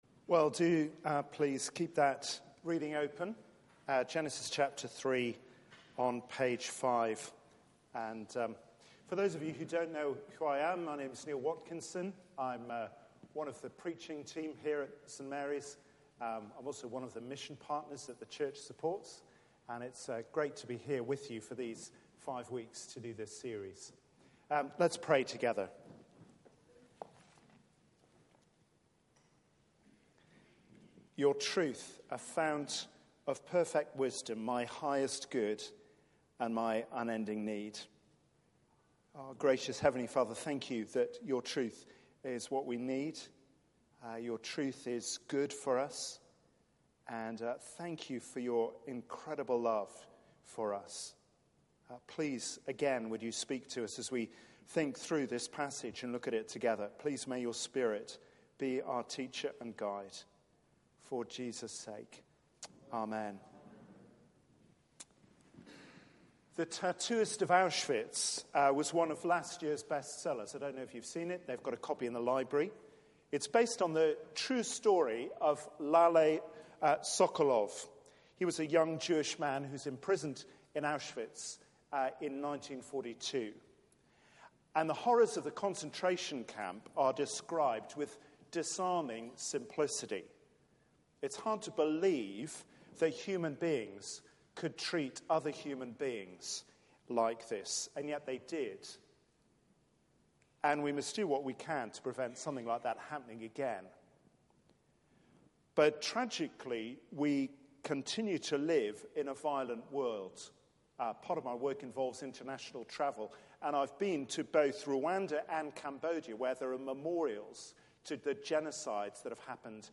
Media for 6:30pm Service on Sun 13th Jan 2019 18:30 Speaker
Series: Knowing who you are Theme: Corrupted by sin Sermon